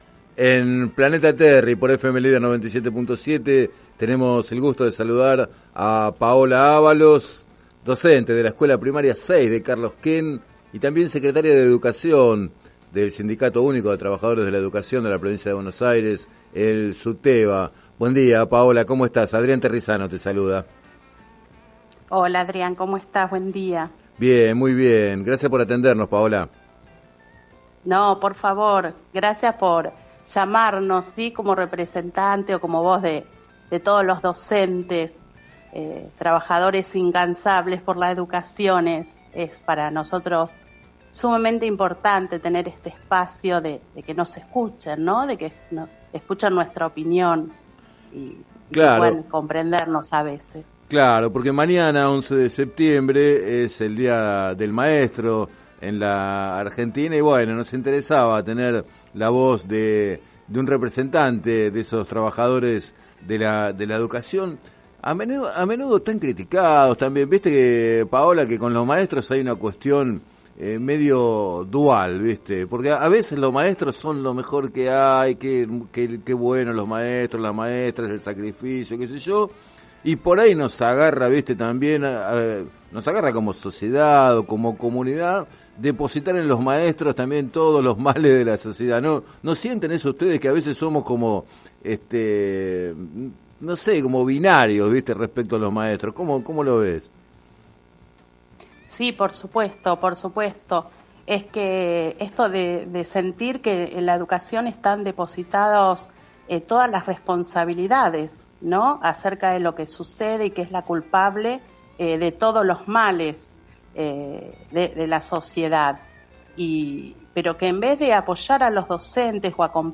Entrevistada en el programa “Planeta Terri” de FM Líder 97.7 con motivo del Día del Maestro